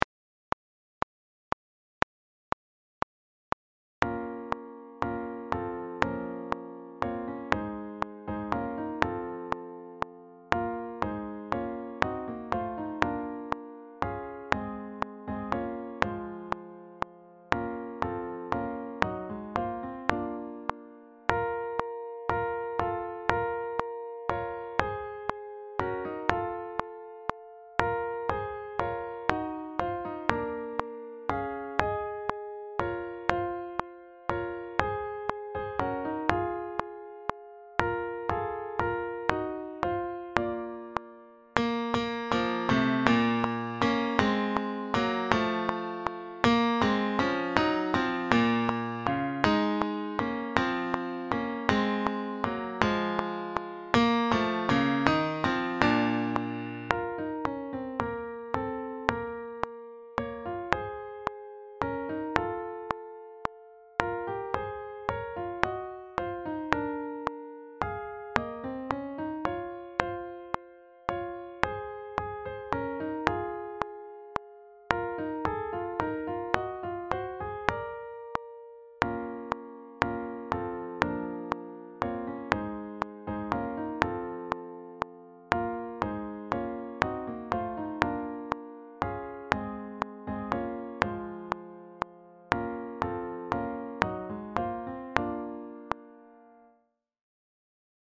Sax Quartets
The "Coventry Carol" is a traditional English carol whose authorship is unknown.
Here we present a more standard SATB or AATB quartet, resulting in some very close harmony and the lower saxes working towards the upper end of their range in the quieter verses. They get to exert themselves more openly in the more animated second verse.
Backing track